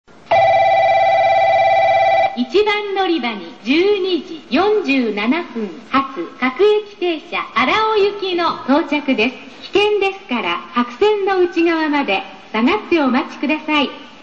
１番のりば 接近放送 普通・荒尾 (70KB/14秒)
九州標準放送です。